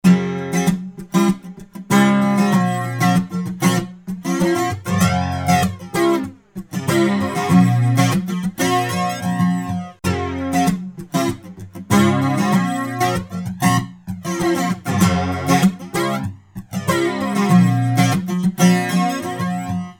flanger effect
gui-flanger1.mp3